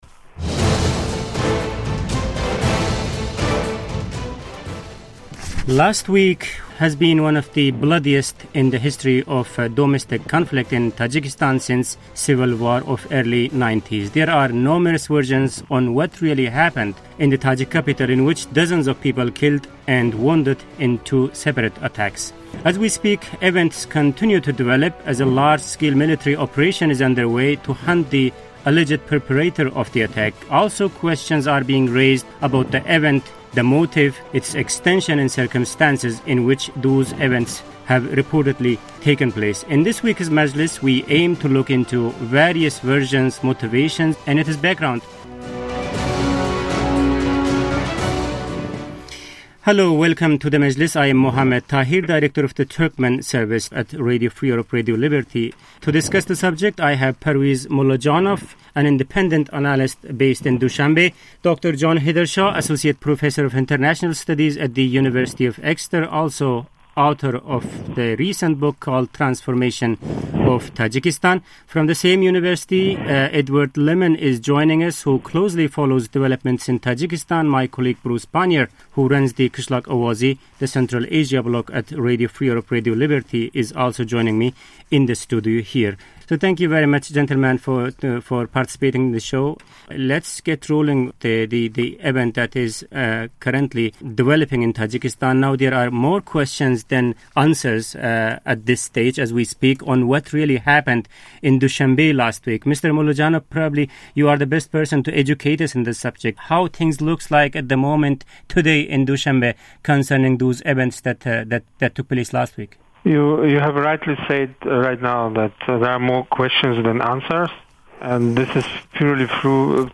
Roundtable: Establishing The Causes Of Tajikistan's Current Crisis